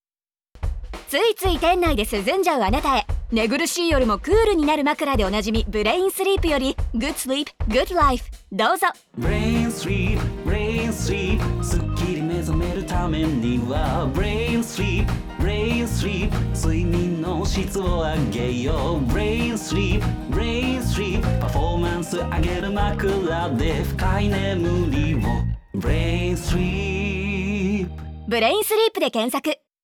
本クリエイティブは、ラジオの時報CM素材をコンビニでの店内放送用にアレンジしたものです。
「店内」という空間でも製品情報が確実に伝わるよう、音源の冒頭と最後にナレーションを追加しました。